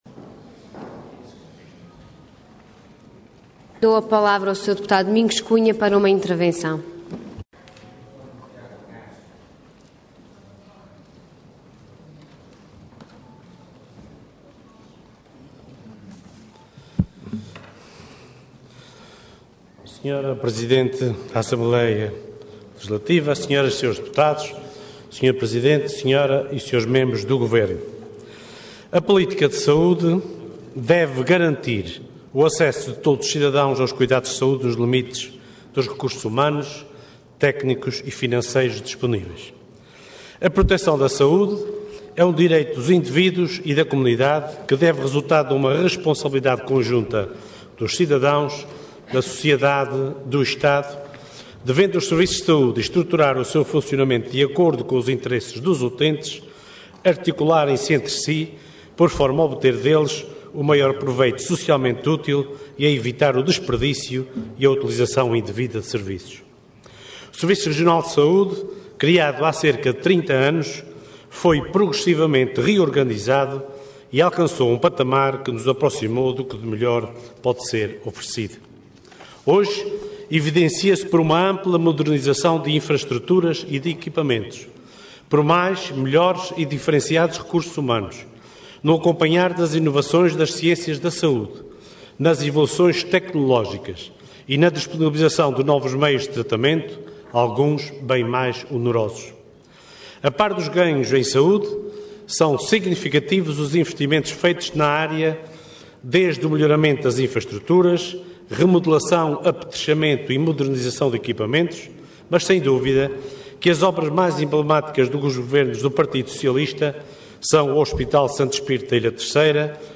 Intervenção Intervenção de Tribuna Orador Domingos Cunha Cargo Deputado Entidade PS